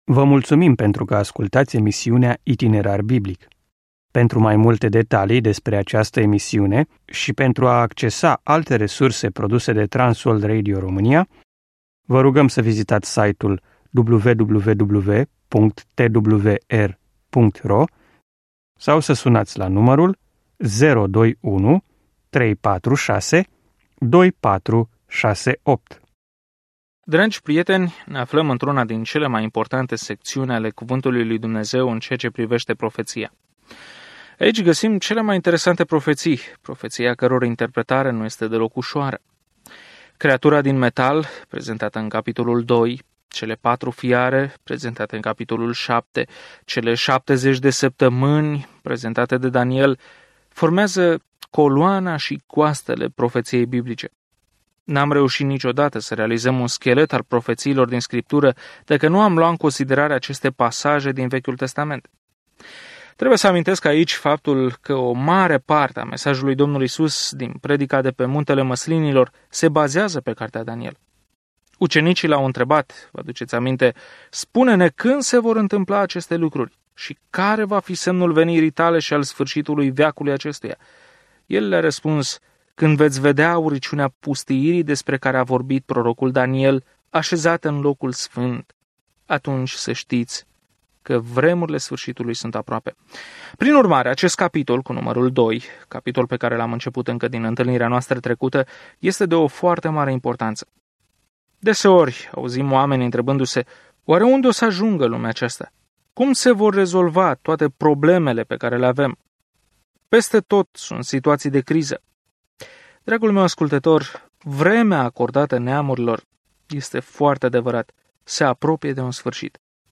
Scriptura Daniel 2:12-38 Ziua 3 Începe acest plan Ziua 5 Despre acest plan Cartea lui Daniel este atât o biografie a unui om care a crezut pe Dumnezeu, cât și o viziune profetică despre cine va conduce lumea în cele din urmă. Călătoriți zilnic prin Daniel în timp ce ascultați studiul audio și citiți versete selectate din Cuvântul lui Dumnezeu.